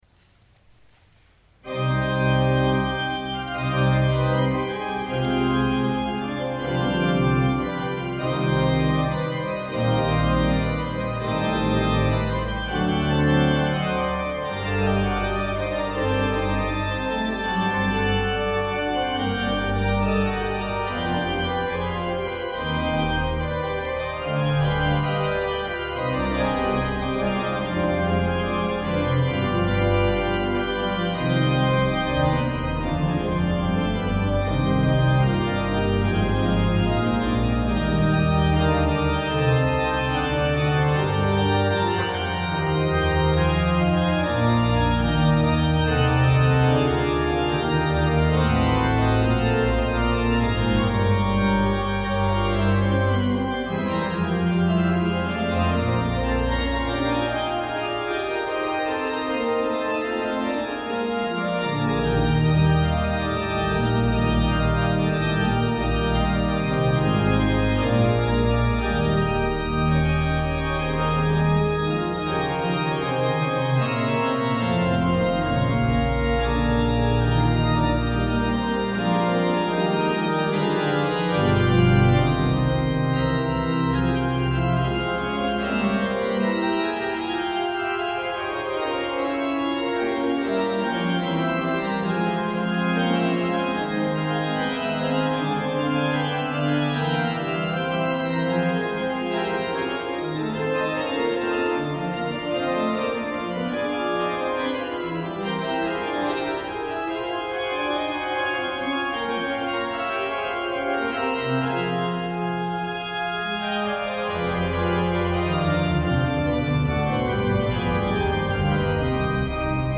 à l'orgue de St Agricol d'Avignon